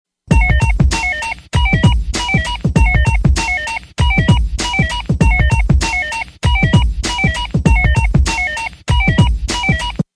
Dance - Electro